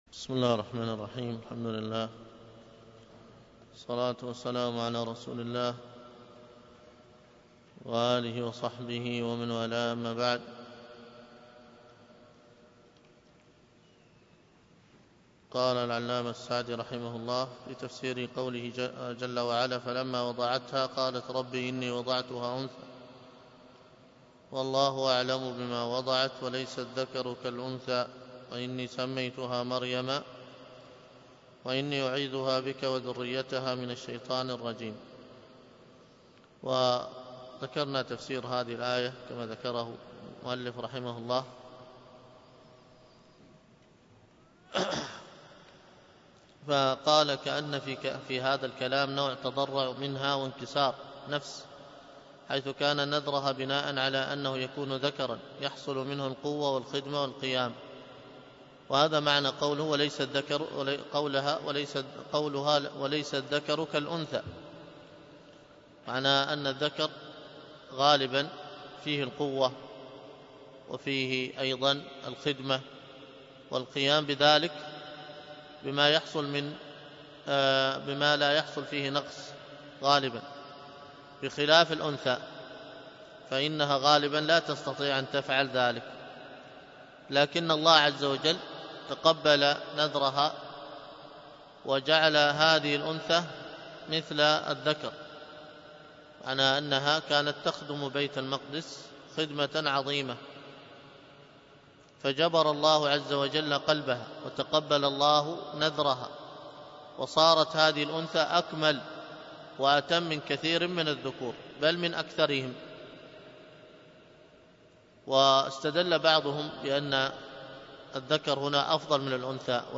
الدروس القرآن الكريم وعلومه